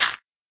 gravel1.ogg